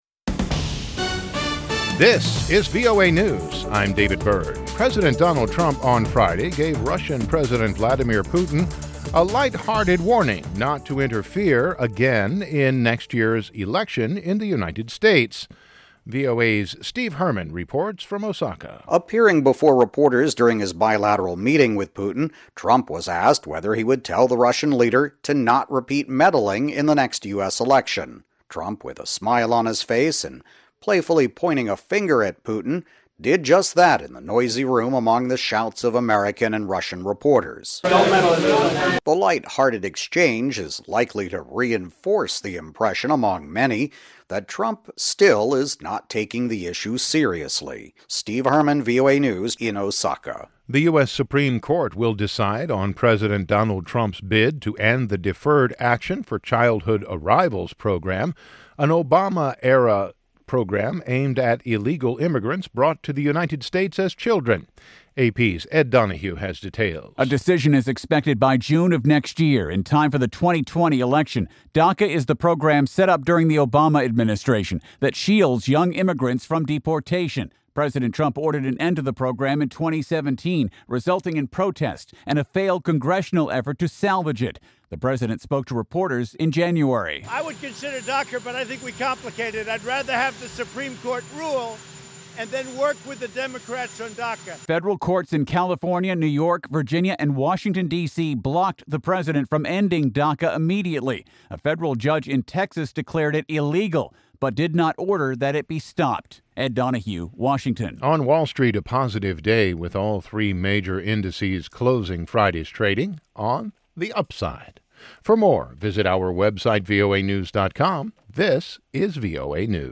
We bring you reports from our correspondents and interviews with newsmakers from across the world.
Tune in at the top of every hour, every day of the week, for the 5-minute VOA Newscast.